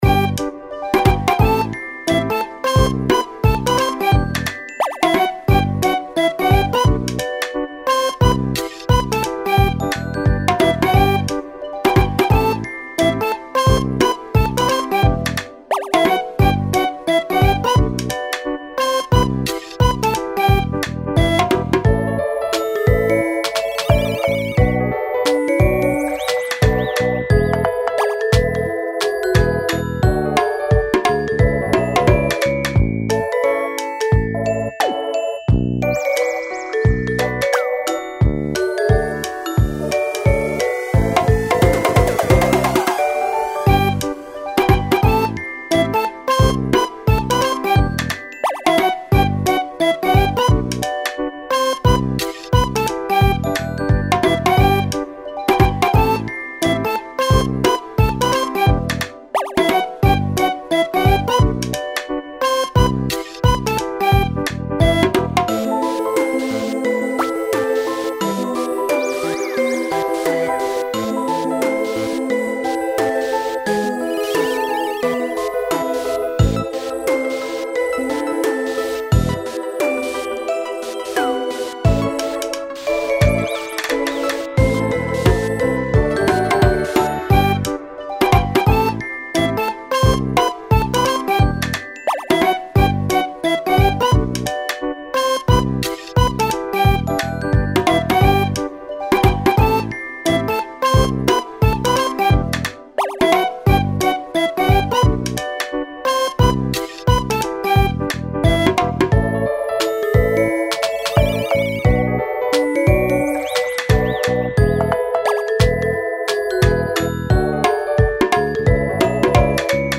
Youtube等の生配信の際のオープニング・待機用BGMを想定して制作した、可愛い雰囲気のBGMです。